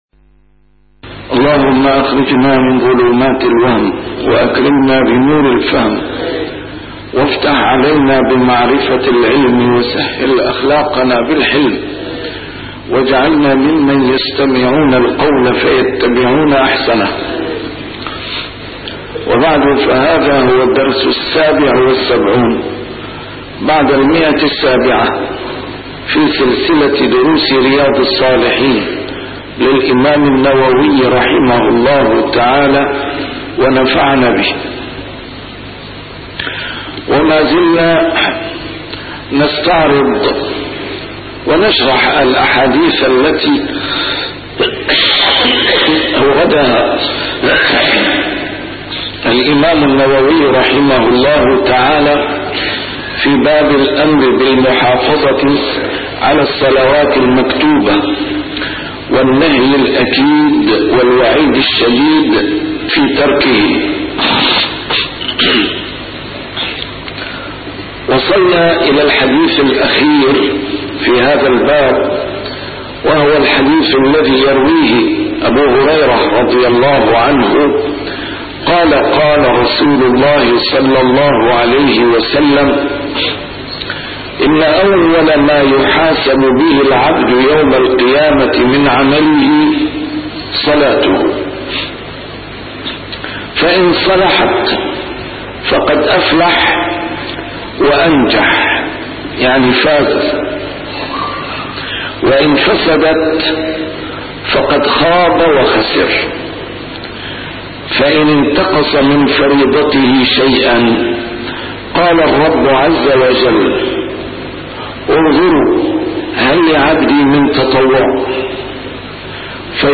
شرح كتاب رياض الصالحين - A MARTYR SCHOLAR: IMAM MUHAMMAD SAEED RAMADAN AL-BOUTI - الدروس العلمية - علوم الحديث الشريف - 777- شرح رياض الصالحين: المحافظة على الصلوات المكتوبات